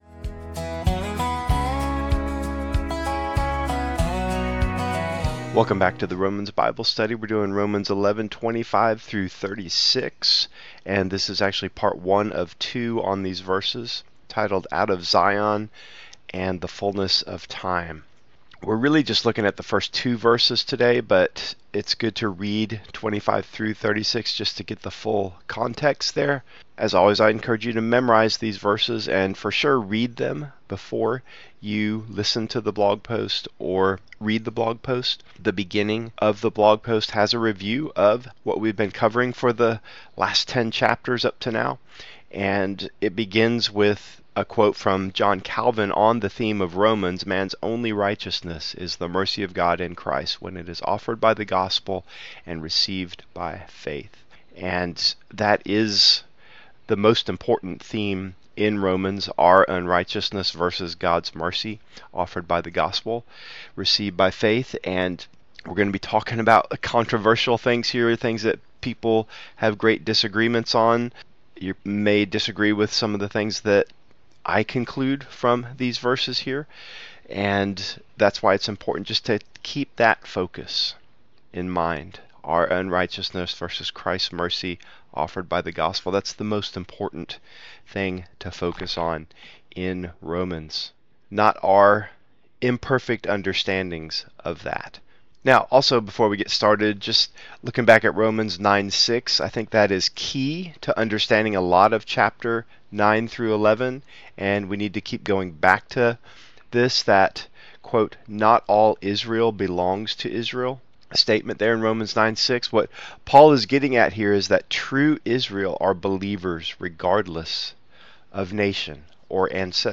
Listen to the study here: Romans 11:25-36